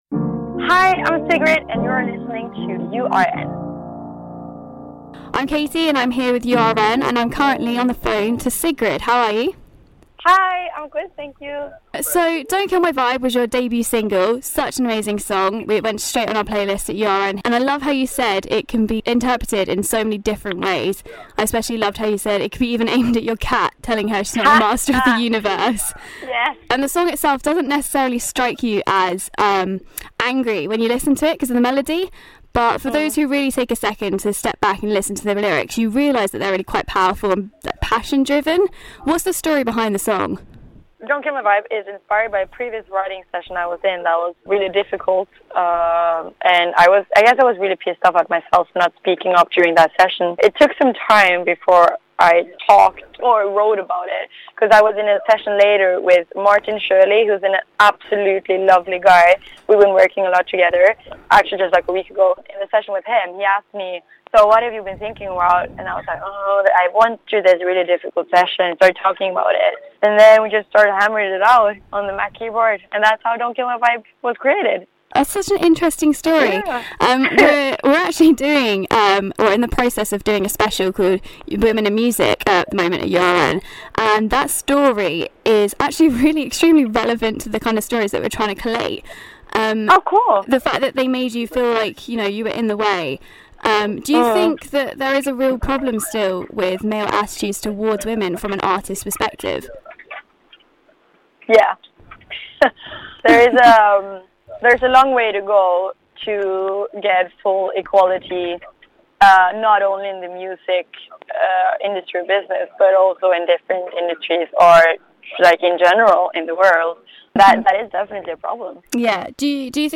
Sigrid Interview